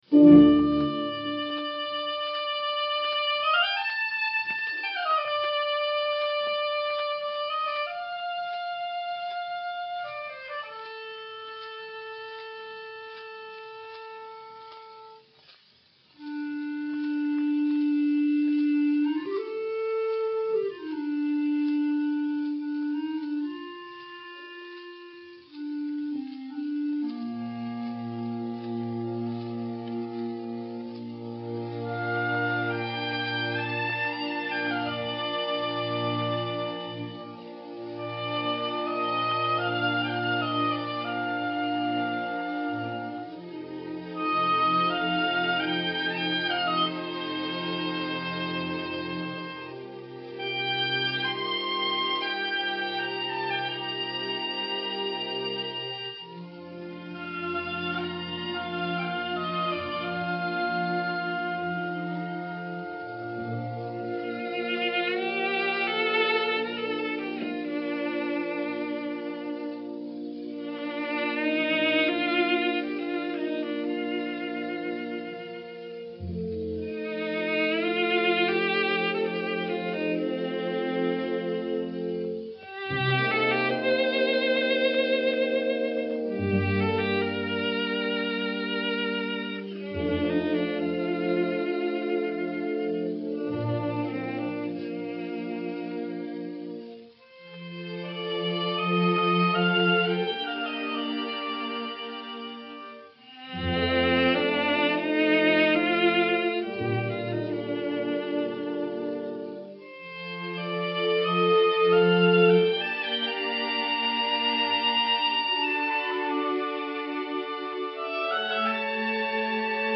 Les danses viennent ensuite, et forment une sorte de triptyque musical : d'abord une danse grecque où les motifs très caractéristiques se développent avec une harmonieuse eurythmie ; puis une expressive et douloureuse scène pantomimique : la Troyenne regrettant sa patrie perdue ; enfin une danse tour à tour emportée et gracieuse, après quoi revient le chœur de glorification.
violoncelle
hautbois